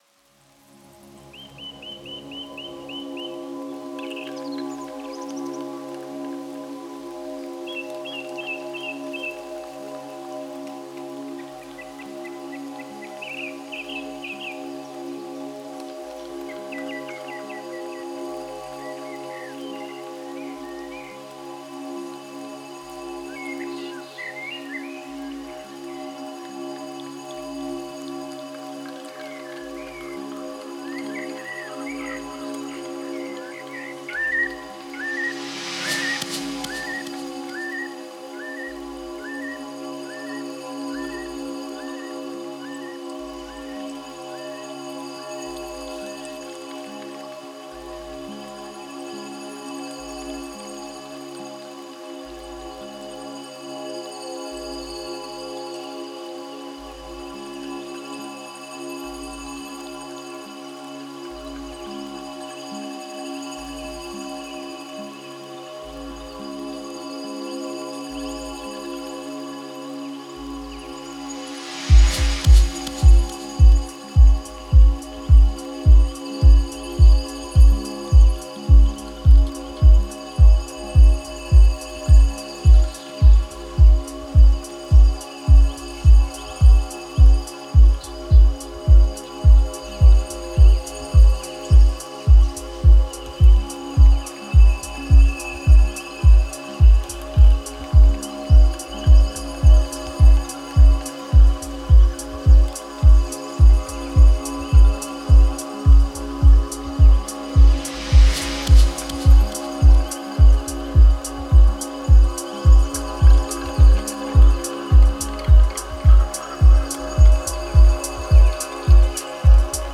Genre: Dub Techno/Techno.